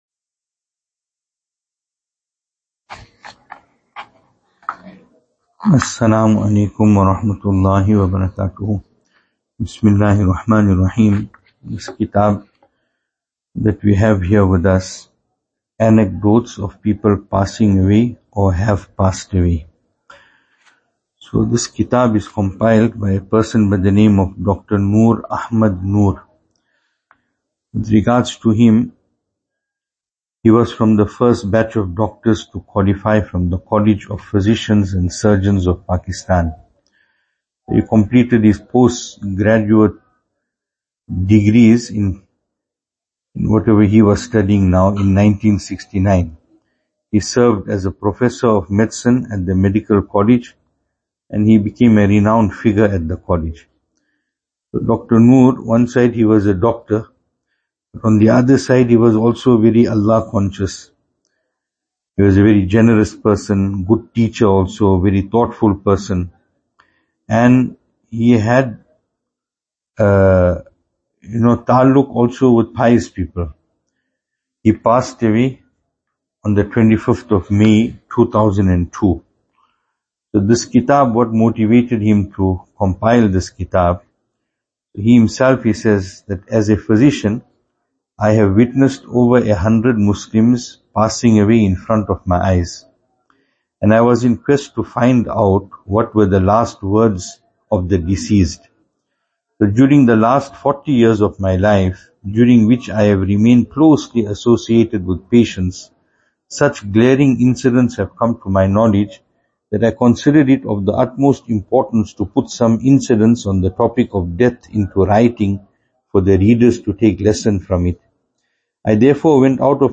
Venue: Albert Falls , Madressa Isha'atul Haq
Service Type: Majlis